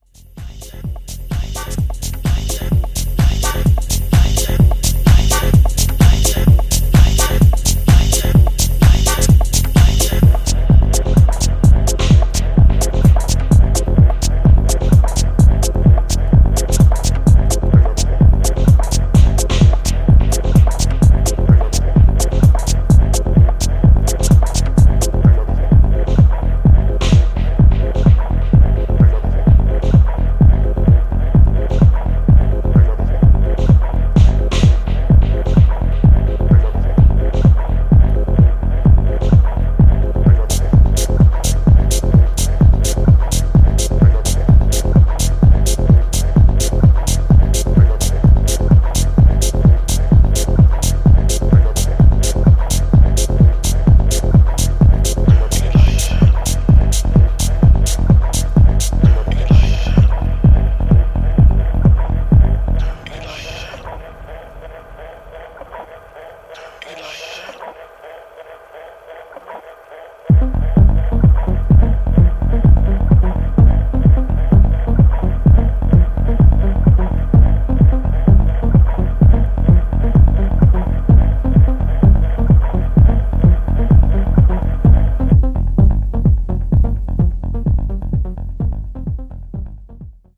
微かに漂うトビ要素をベースラインが牽引し続けるミニマルな